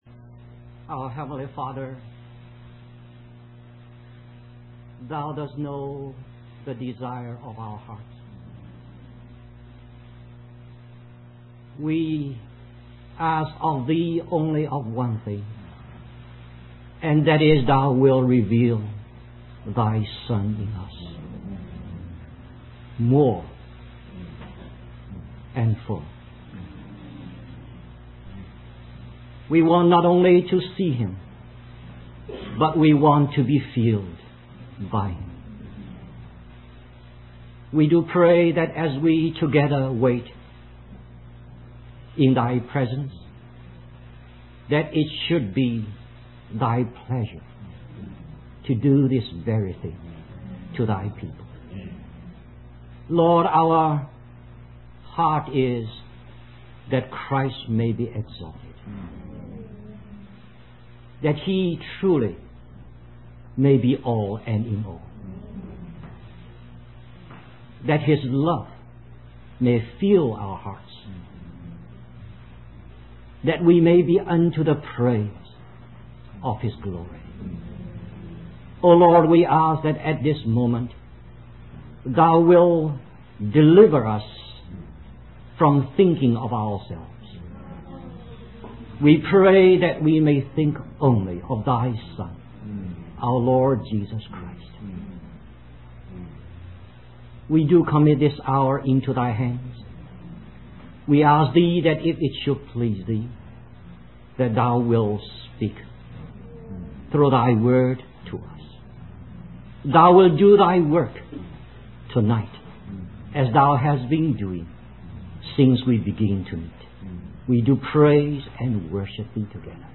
In this sermon, the preacher discusses the glorious scenes in heaven described in the book of Revelation.